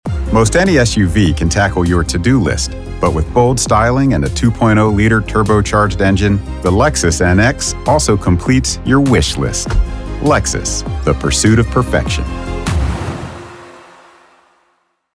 Commercial / Bold / Luxury
North American General American, North American US Mid-Atlantic
Middle Aged